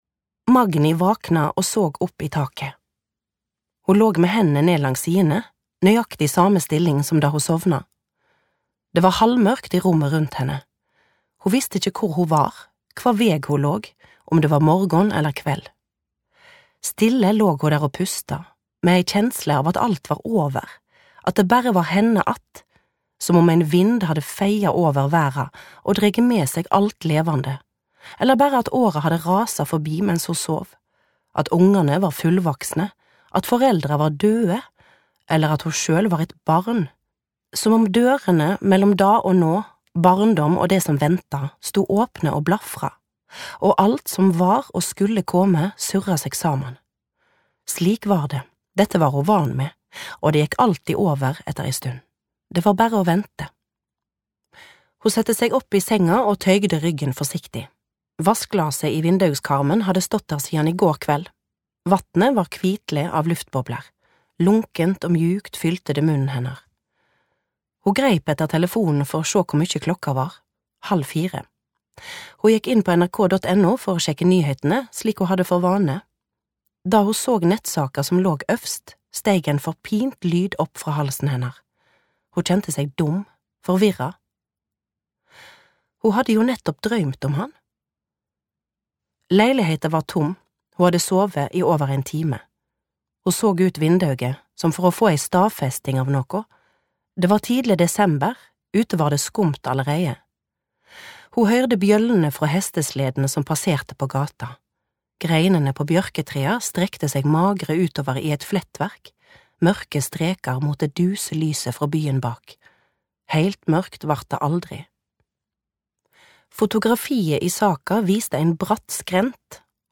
noveller
Utdraget er hentet fra lydbokbokutgaven